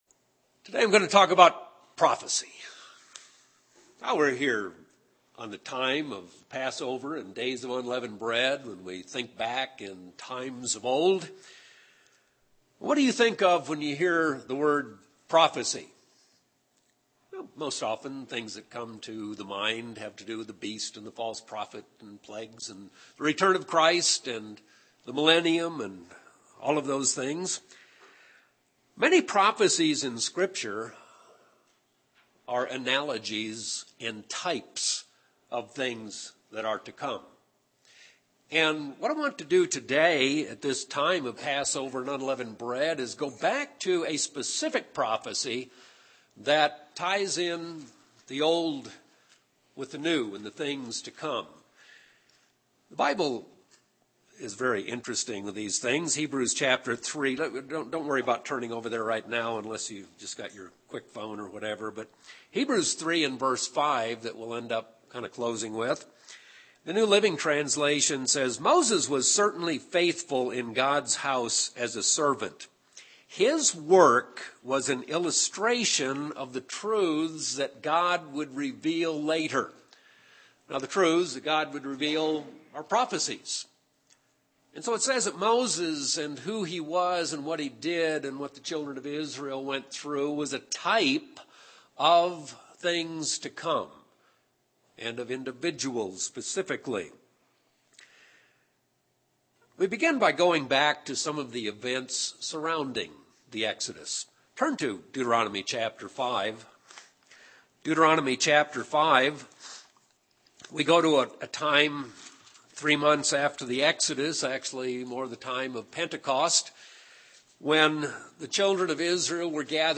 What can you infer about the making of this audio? Given in Albuquerque, NM